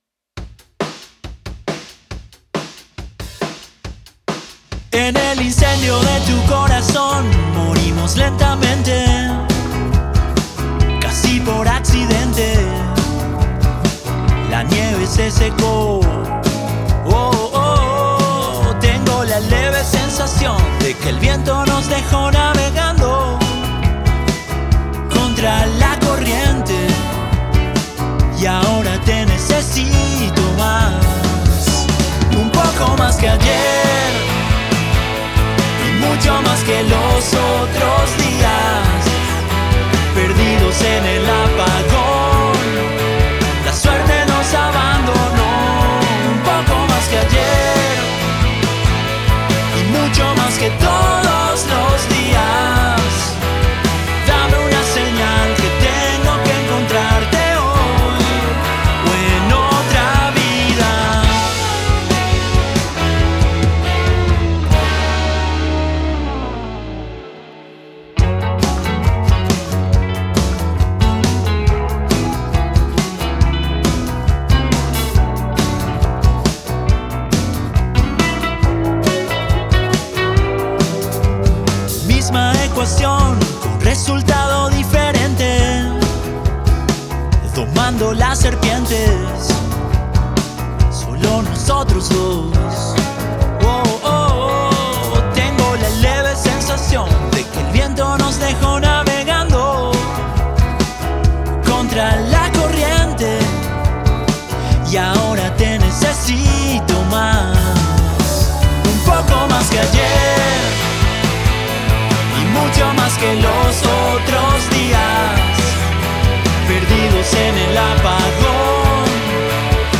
lleva por nombre la canción de la banda paraguaya de rock
Guitarras
Bajos
Batería y Percusión
Coros
Teclados y Sintetizadores